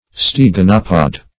steganopod - definition of steganopod - synonyms, pronunciation, spelling from Free Dictionary Search Result for " steganopod" : The Collaborative International Dictionary of English v.0.48: Steganopod \Ste*gan"o*pod\, n. (Zool.) One of the Steganopodes.